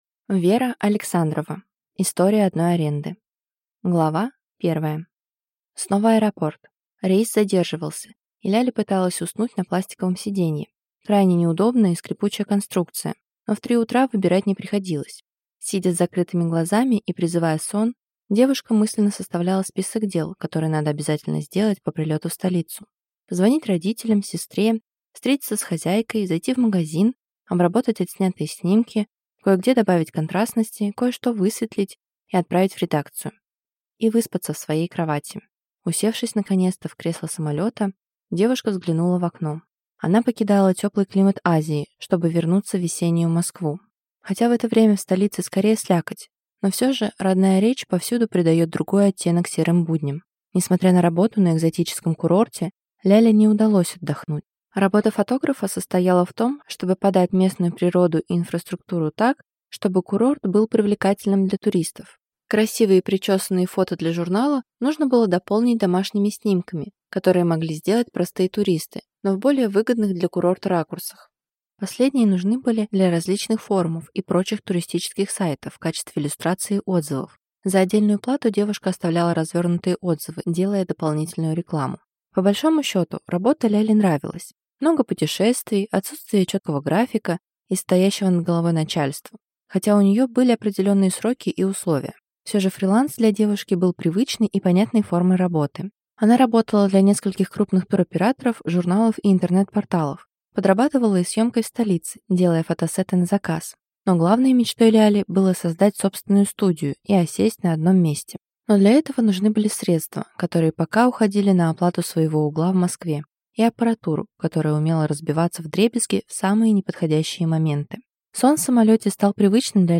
Аудиокнига История одной аренды | Библиотека аудиокниг